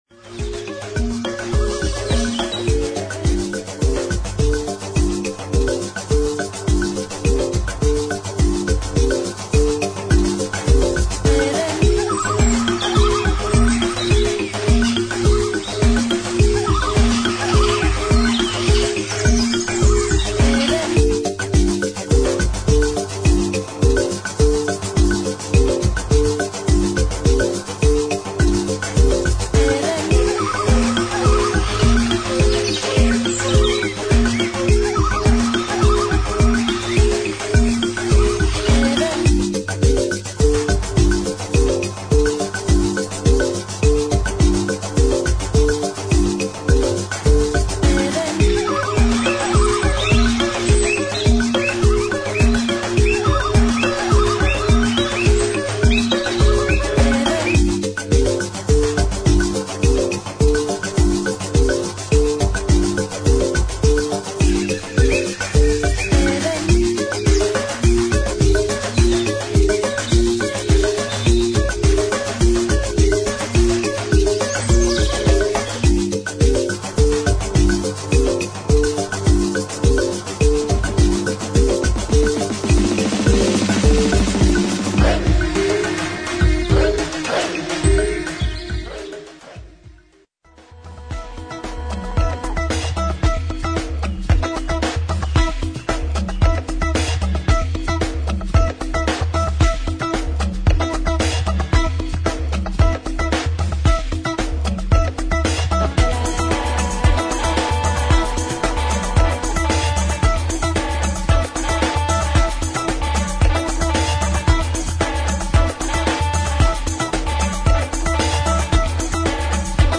プリミティブなパーカッションとエレピ、ハープ、電子音が一体となった
バウンシーなリズムとスティール・パンの音色がトロピカルにグルーヴする
カリンバのミニマルなリフに、カット・アップされたヴォイス・サンプルや鳥の鳴き声が効果的に絡む